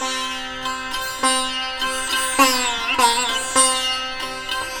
100-SITAR5-R.wav